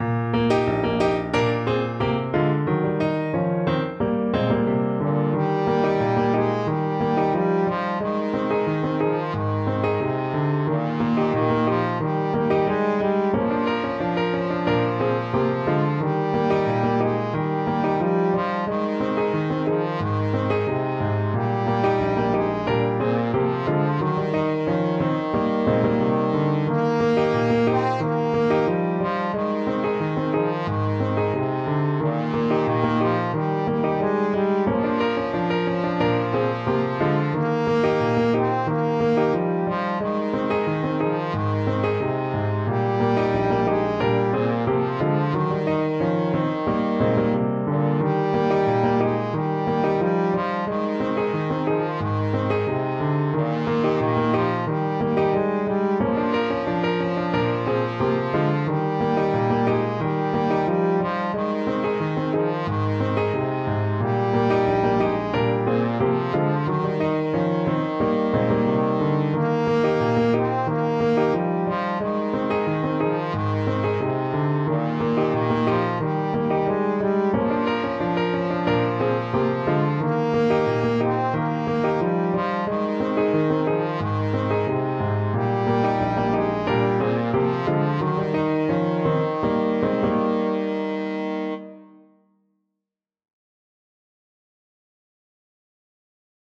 Traditional Trad. Bury Me Beneath the Willow Trombone version
Trombone
4/4 (View more 4/4 Music)
Bb3-C5
Eb major (Sounding Pitch) (View more Eb major Music for Trombone )
Moderato =c.90